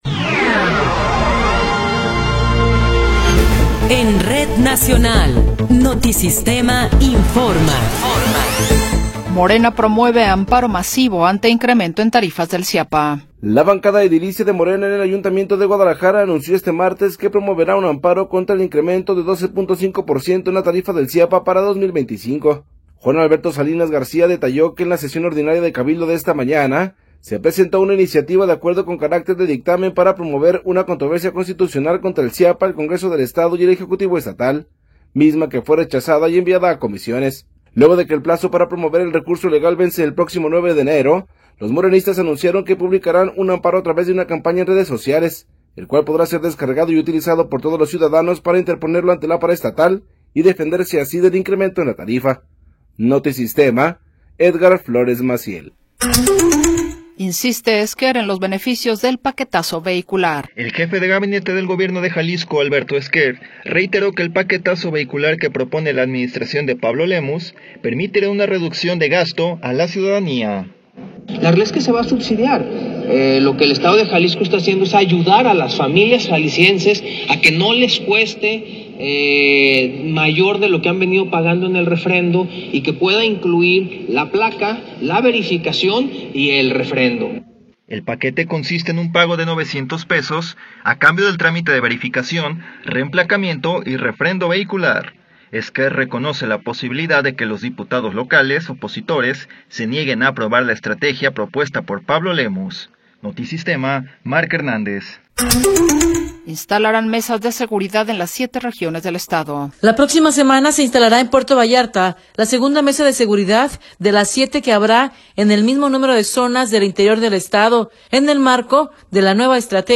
Noticiero 16 hrs. – 10 de Diciembre de 2024
Resumen informativo Notisistema, la mejor y más completa información cada hora en la hora.